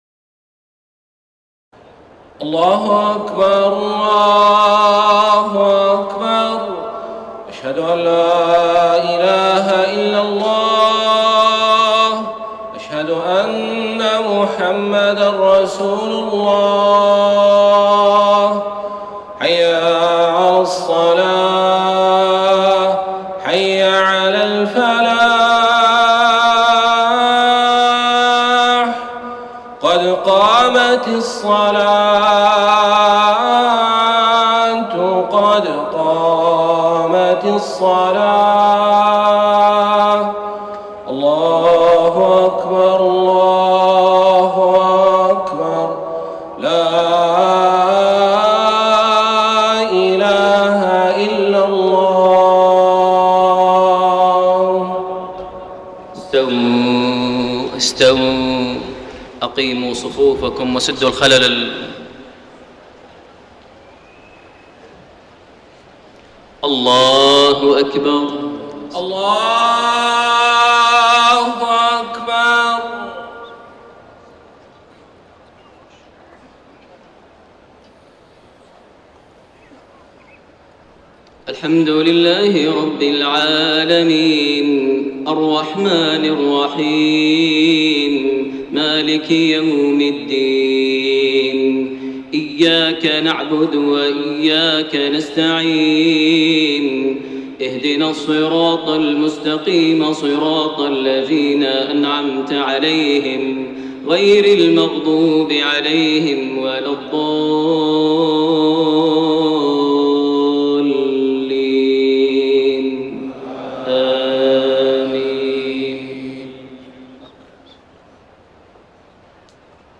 صلاة المغرب 18 رجب 1433هـ خواتيم سورة البقرة 284-286 > 1433 هـ > الفروض - تلاوات ماهر المعيقلي